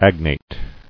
[ag·nate]